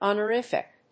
音節hon・or・if・ic 発音記号・読み方
/ὰnərífɪk(米国英語), `ɔnərífɪk(英国英語)/